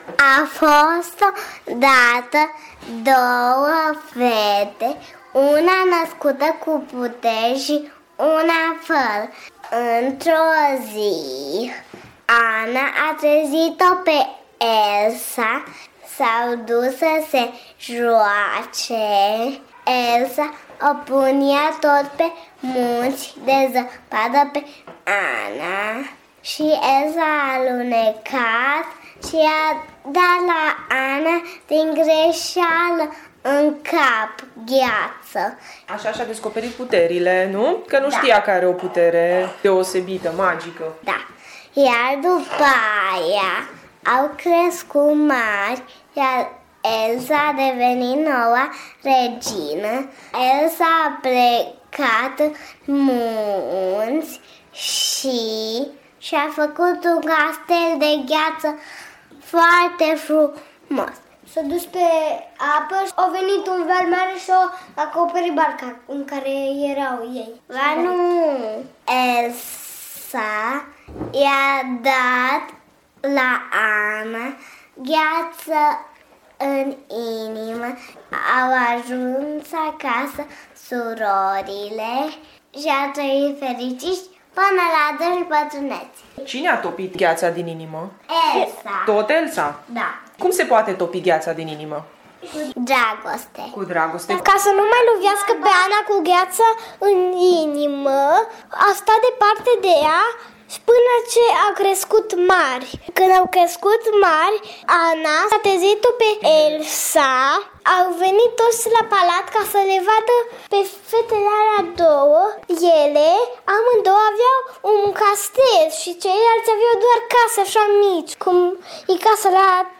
Copiiii ne spun povestea, așa cum au înțeles-o ei.